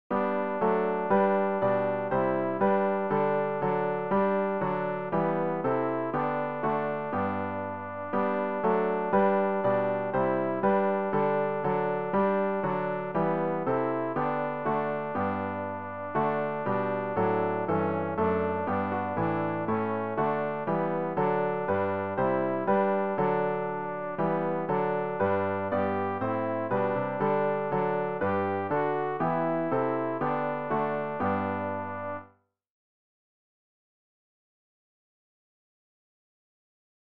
g-693-bei-dir-jesu-will-ich-bleiben-alt.mp3